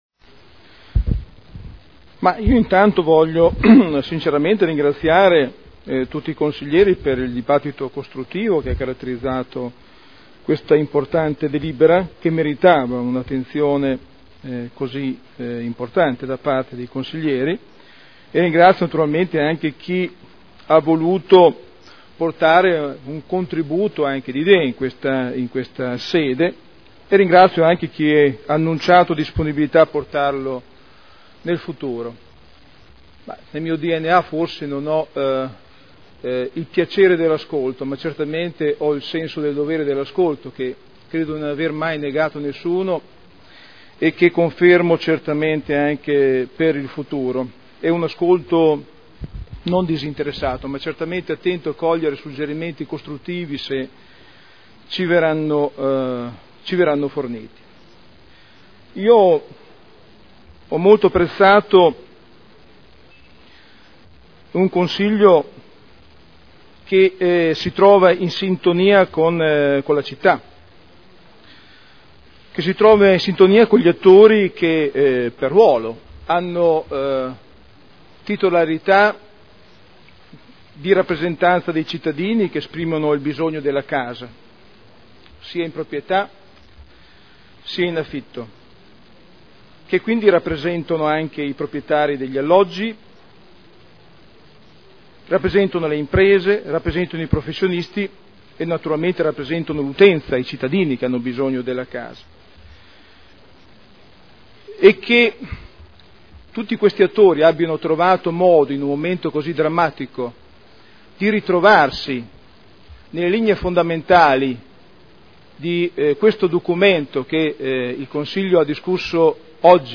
Seduta del 19 marzo Proposta di deliberazione Programma per l’edilizia sociale – Principi ed indirizzi – Per un nuovo piano abitativo sociale (Qualità – Sostenibilità – Equità) Dibattito